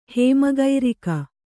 ♪ hēma gairika